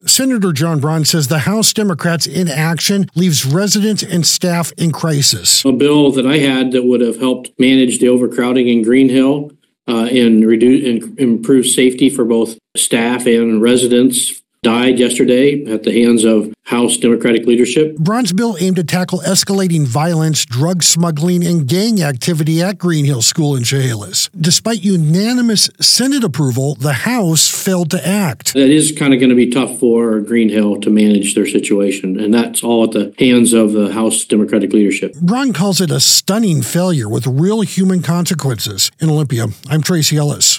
AUDIO: House Democrats fumble justice-reform bill, leaving juvenile-justice facilities in crisis - Senate Republican Caucus